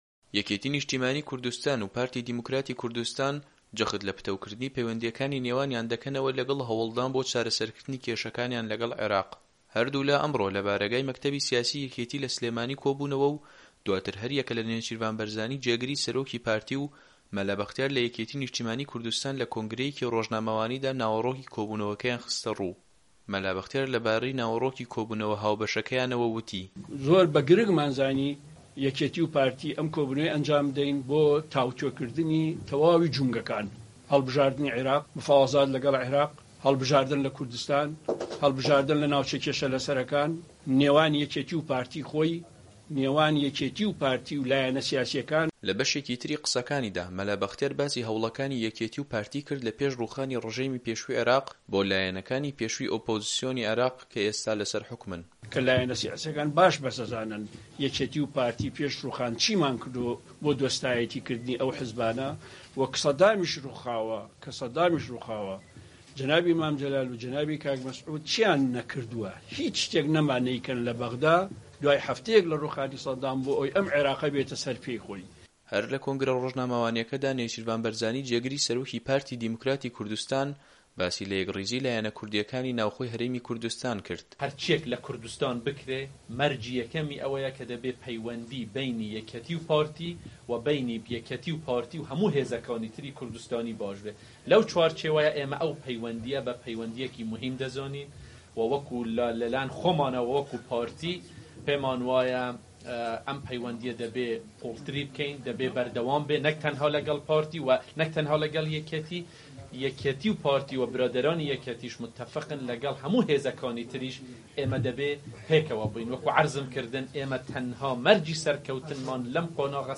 دوای کۆبوونەوەکەیان کە لە سلێمانی بەڕێوەچوو هەریەکە لە نێچیرڤان بارزانی جێگری سەرۆکی پارتی و مەلا بەختیار لە یەکێتی نیشتیمانی کوردستان لە کۆنگرەیەکی رۆژنامەوانیدا ناوەڕۆکی گفتوگۆکانیان خستەڕوو.